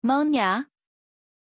Pronunciation of Monya
pronunciation-monya-zh.mp3